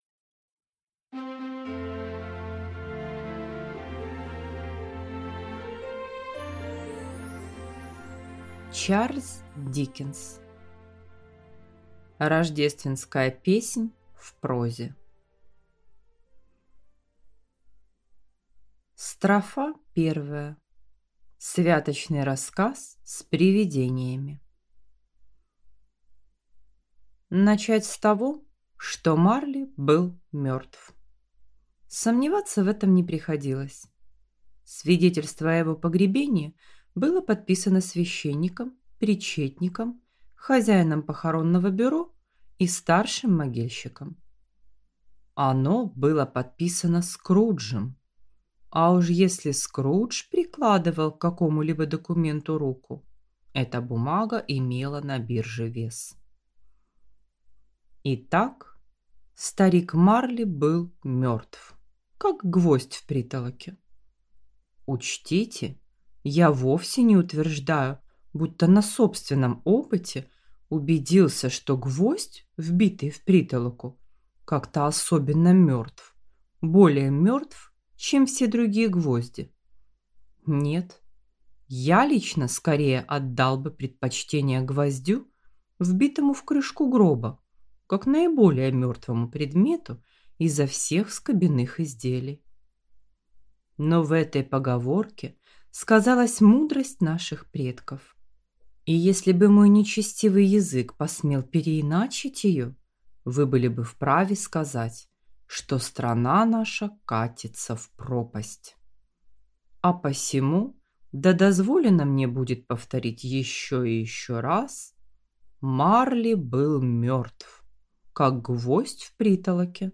Аудиокнига Рождественская песнь в прозе | Библиотека аудиокниг